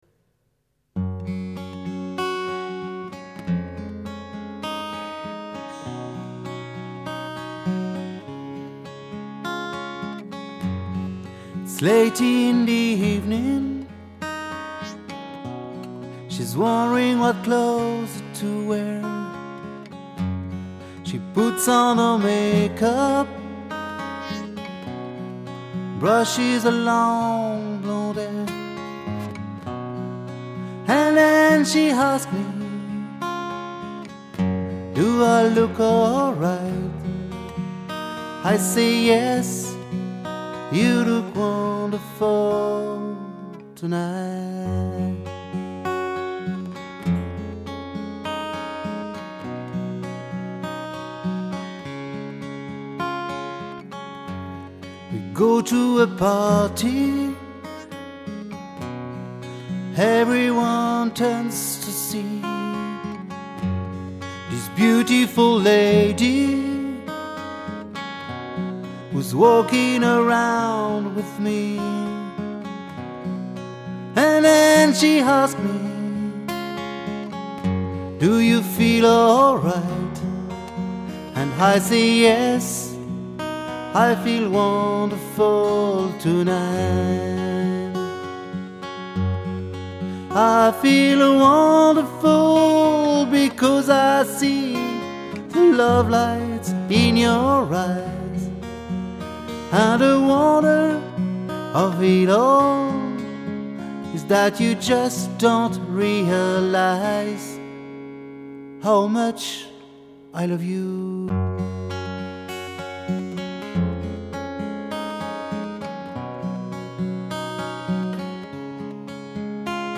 il s'agit de 2 cover acoustic faits ce week-end,
(guitare et voix 'one shot')
oui, dans ce contexte, je joue en même temps que je chante,